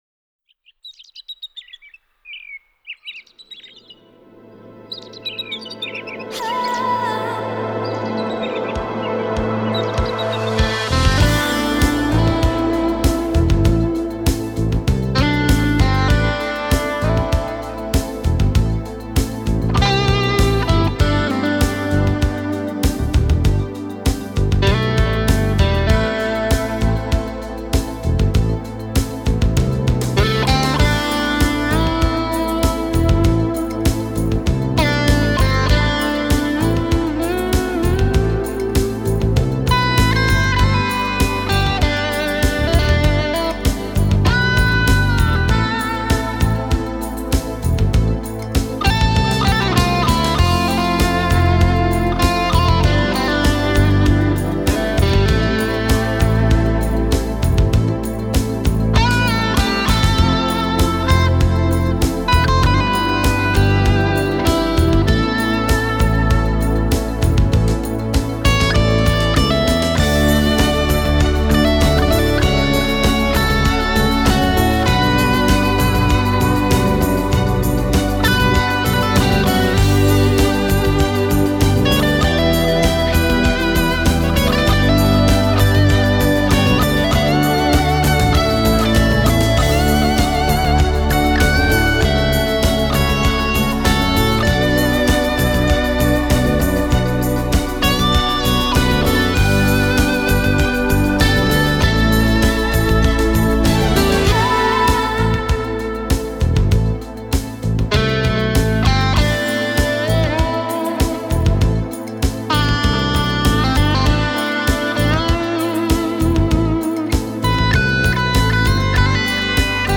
音乐流派；新世纪音乐 (New Age) / 环境音乐 (Ambient Music)
歌曲风格：轻音乐 (Easy Listening) / 纯音乐 (Pure Music)
抒情 典雅 浪漫 自由 温馨 迷醉
旋律带有生动活泼的青春气息。
音乐的背景是用电子乐器造成的轻松低音和超低效果，高频段的细致金属敲击似是这幅美丽自然的音乐图画中最精彩的部分。